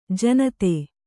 ♪ janate